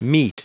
Prononciation du mot meet en anglais (fichier audio)
Prononciation du mot : meet